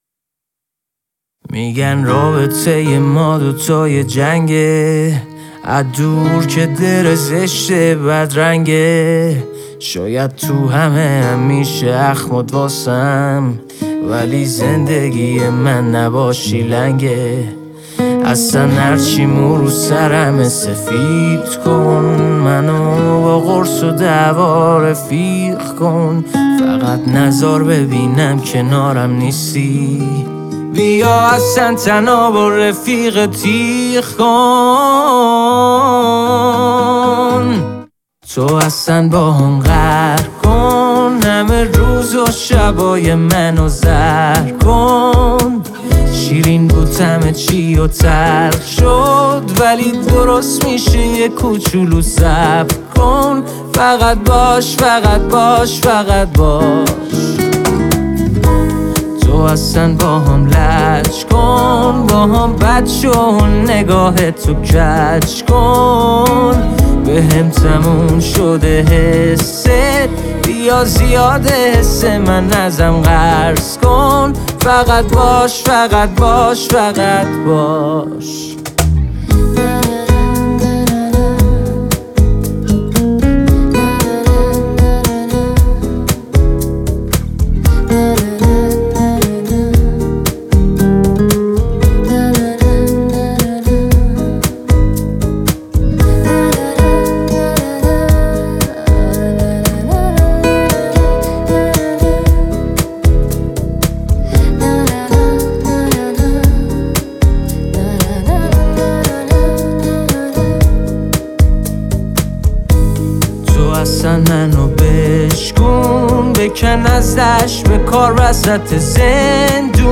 یه آهنگ عاشقانه